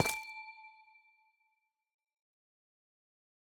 Minecraft Version Minecraft Version latest Latest Release | Latest Snapshot latest / assets / minecraft / sounds / block / amethyst / step14.ogg Compare With Compare With Latest Release | Latest Snapshot
step14.ogg